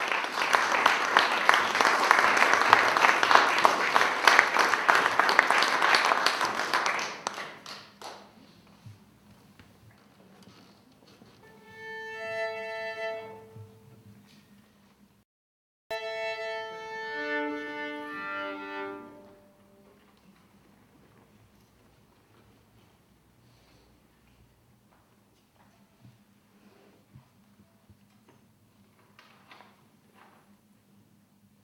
Johann Sebastian Bach - Aus aus der Partita E-Dur für Violine Solo BWV 1006 "Ouverture"